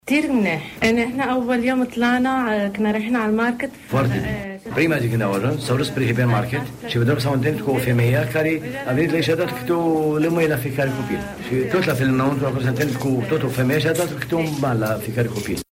Astăzi, într-o întâlnire cu presa, familia siriană care a ajuns în acest prim grup şi-a povestit experienţele traumatizante prin care au trecut.